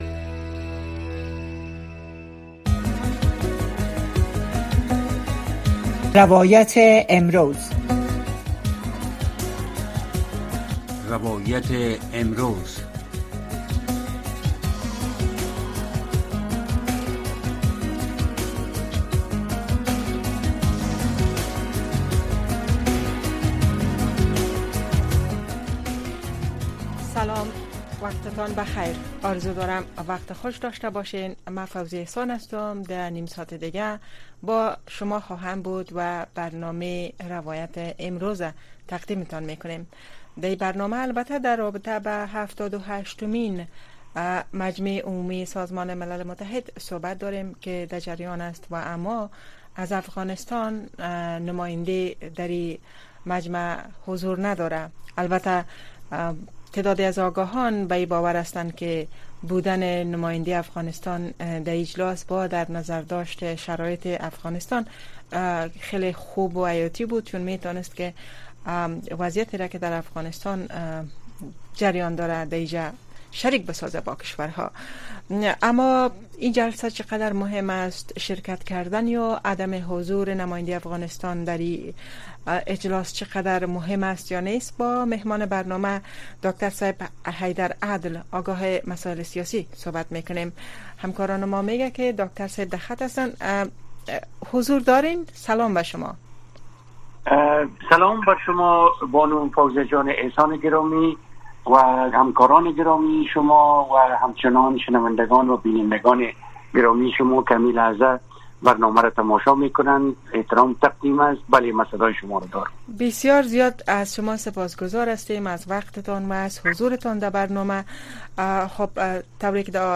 در برنامۀ روایت امروز شرح وضعیت در افغانستان را از زبان شرکت کنندگان این برنامه می‌شنوید. این برنامه شب‌های یک‌شنبه، دوشنبه، سه‌شنبه و پنج‌شنبه از ساعت ٩:۰۰ تا ۹:۳۰ شب به گونۀ زنده صدای شما را در رادیو و شبکه‌های ماهواره‌ای و دیجیتلی صدای امریکا پخش می‌کند.